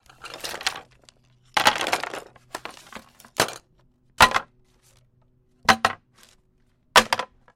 Tag: 130 bpm Electronic Loops Synth Loops 1.35 MB wav Key : Unknown